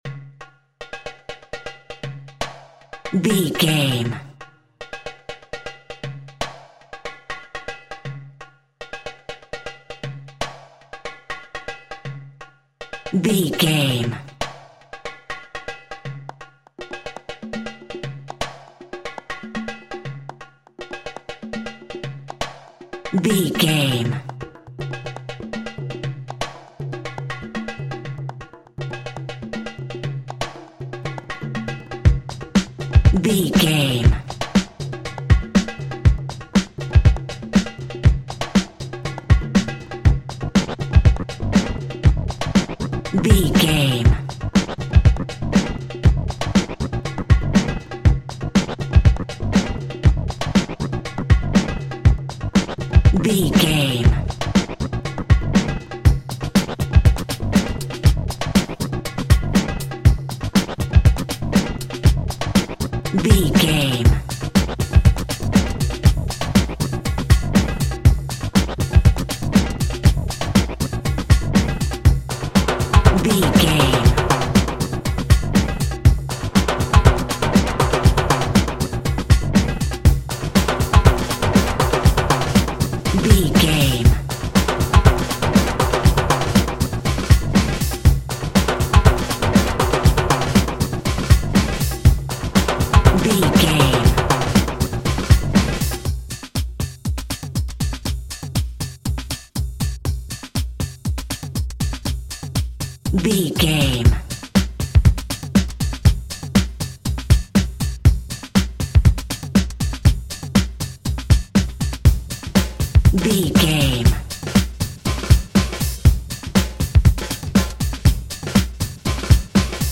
Fast paced
Atonal
world beat
ethnic percussion